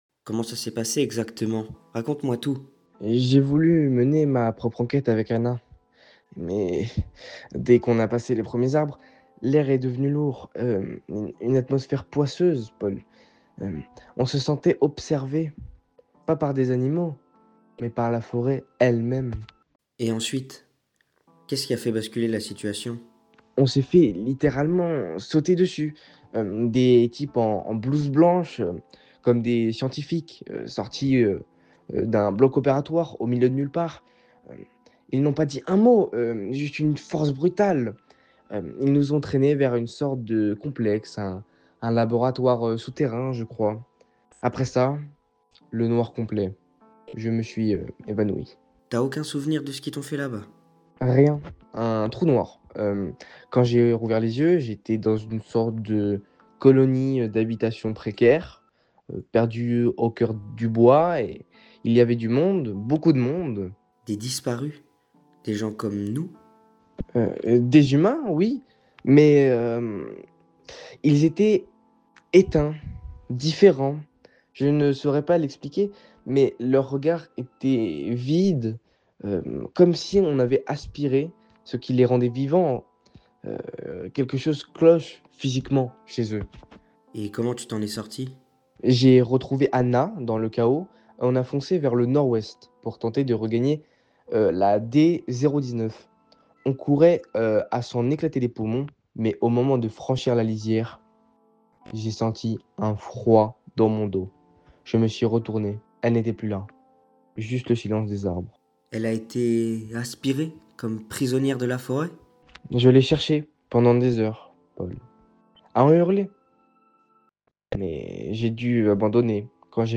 Notez la peur dans sa voix quand il mentionne "Des scientifiques".